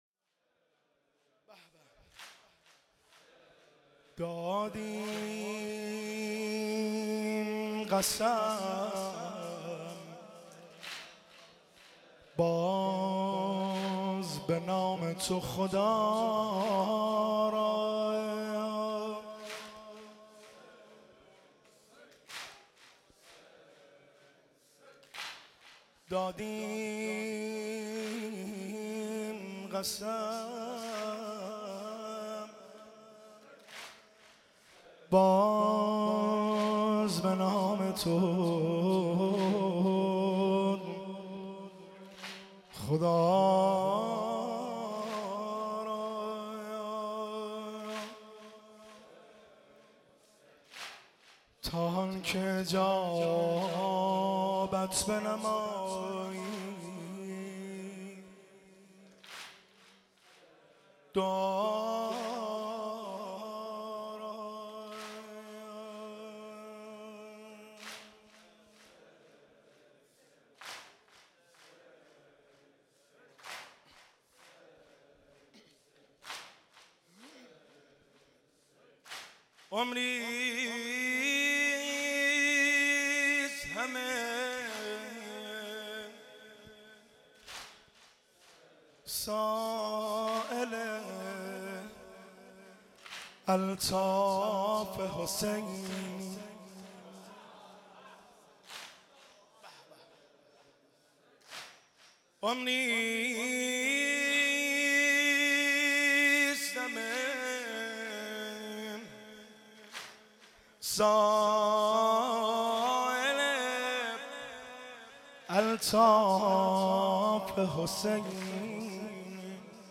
مداحی
در هیات بین الحرمین تهران برگزار شد